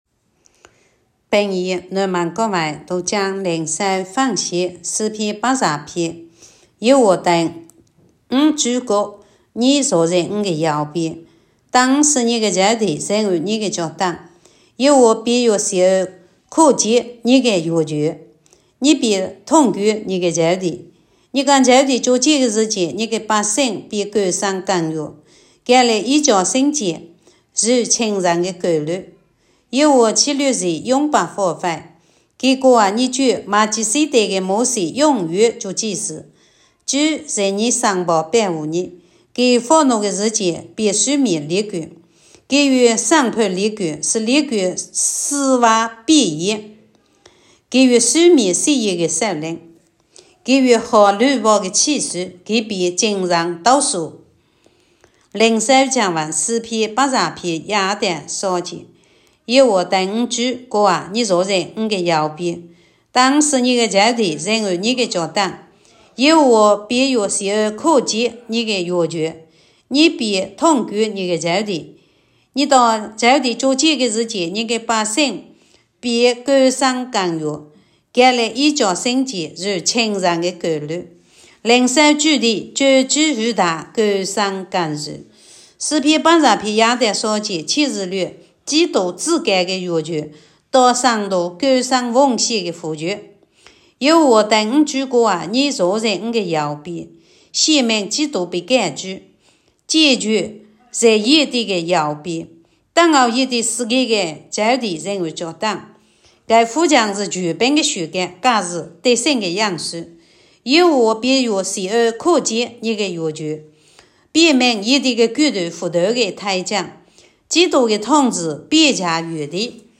平阳话朗读——诗110